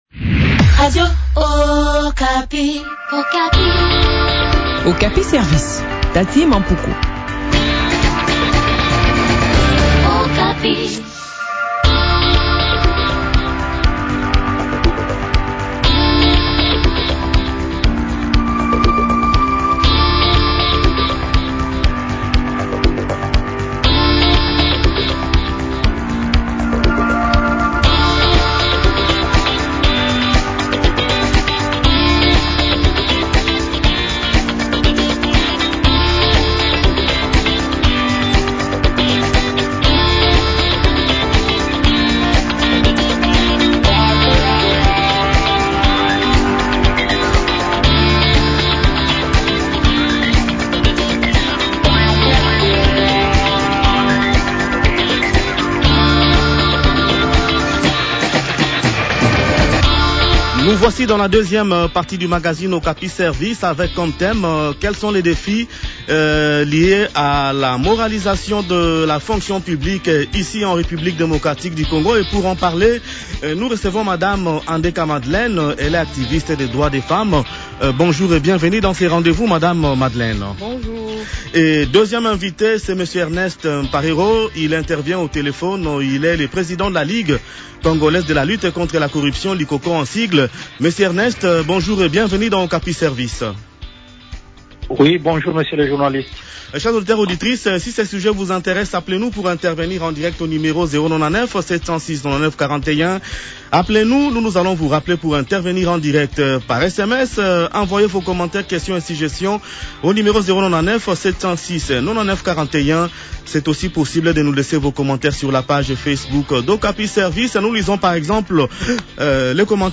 a également pris part à cet entretien.